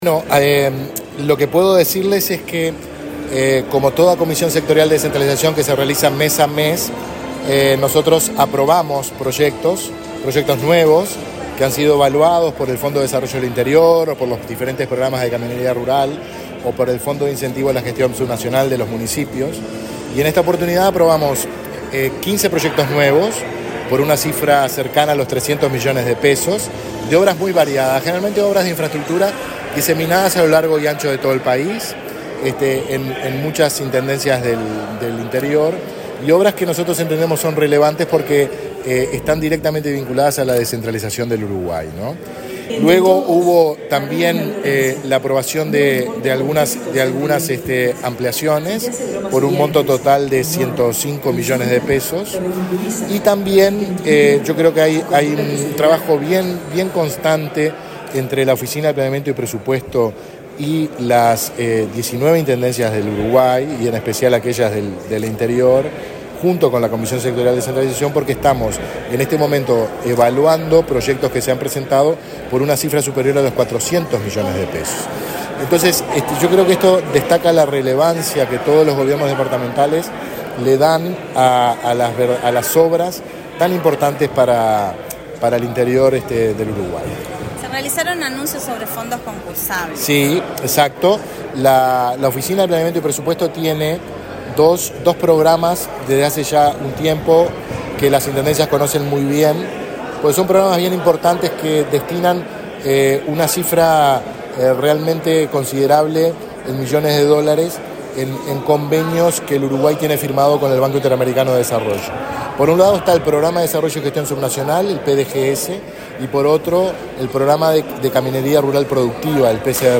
Entrevista a Guillermo Bordoli, de OPP
este jueves 23 en Rocha, sobre el trabajo de la Comisión Sectorial de Descentralización.